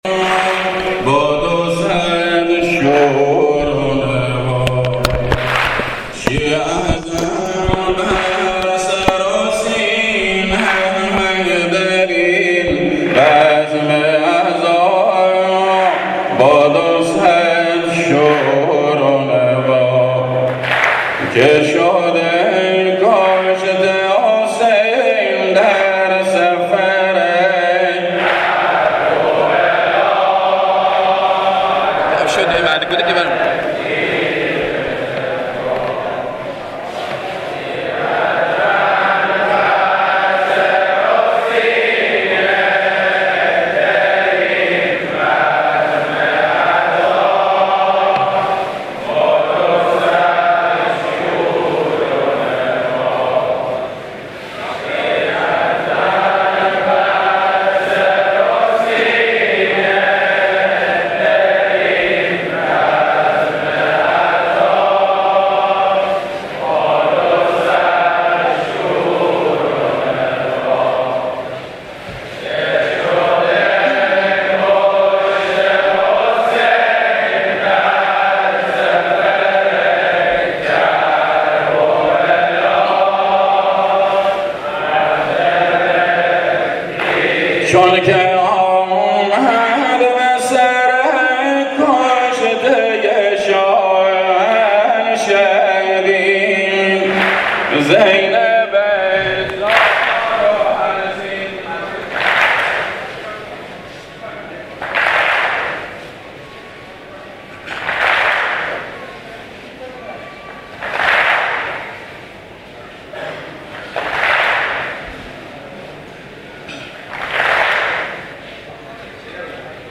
آوای لیان - دانلود نوحه و عزاداری سنتی بوشهر
مراسم سینه زنی محرم ۱۳۹۴ | مسجد امام خمینی “ره”